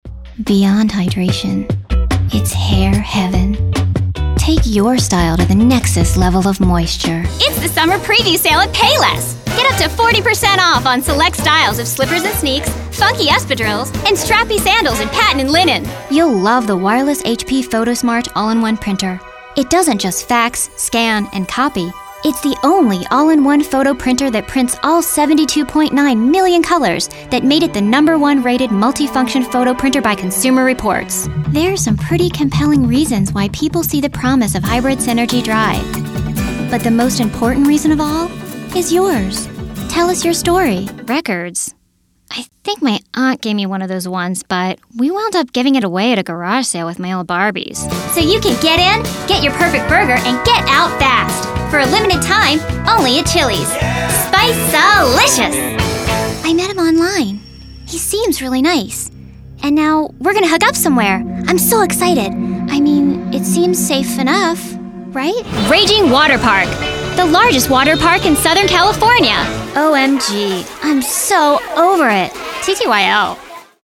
Voice range: kids (girl/boy), teen, and adult female.
Voice quality: Youthful, vibrant, energetic, quirky, sincere, genuine, sweet, deadpan.
COMMERCIAL 💸